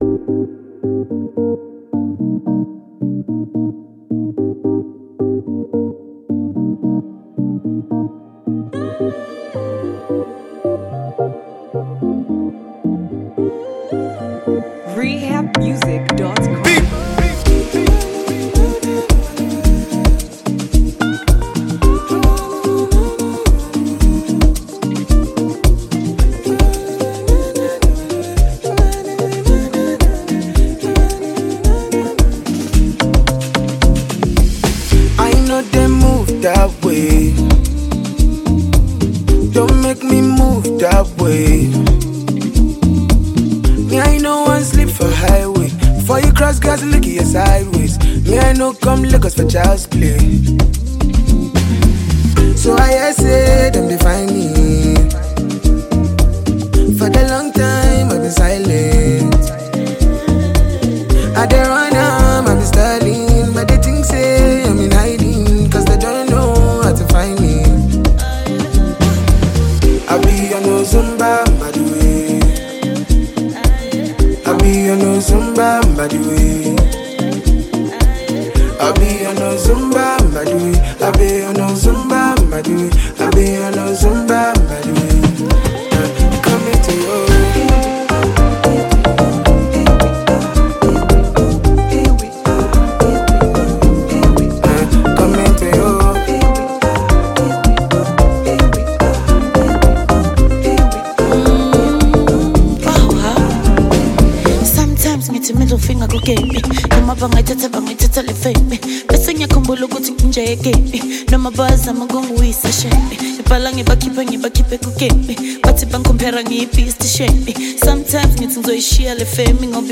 The new remix features South African singer